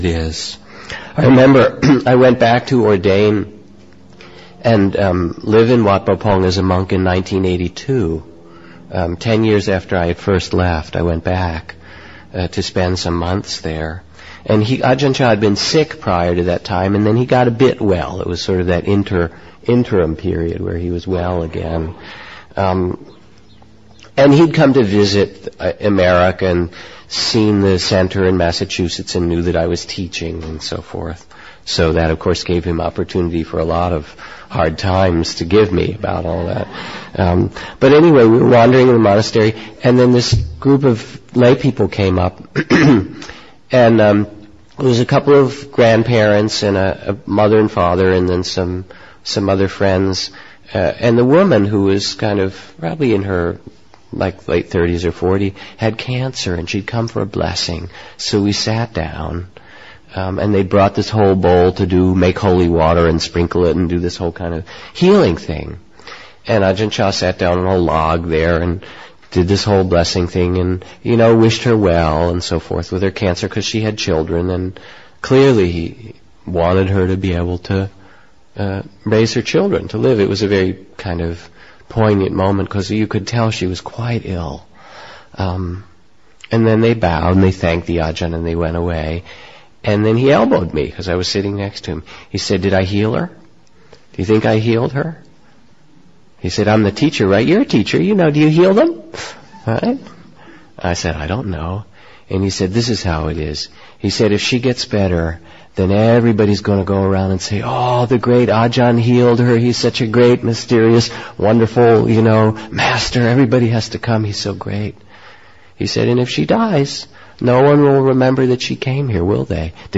Told by Jack Kornfield.